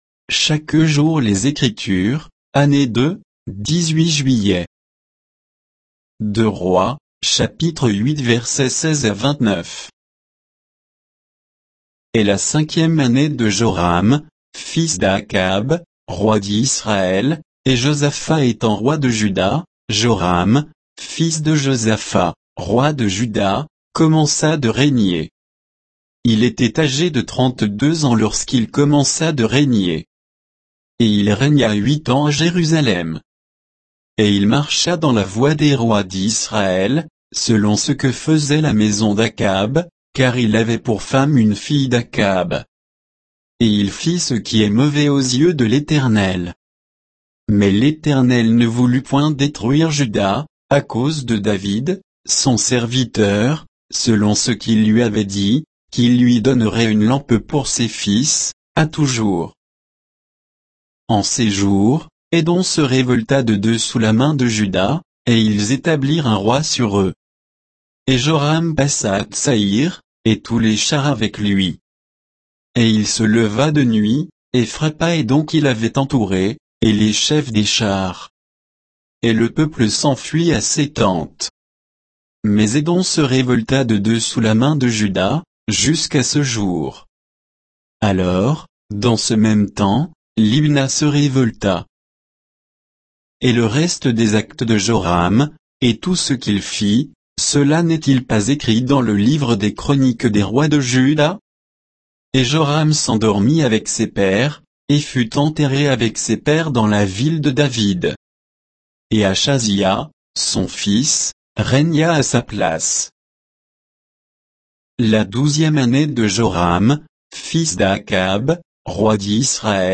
Méditation quoditienne de Chaque jour les Écritures sur 2 Rois 8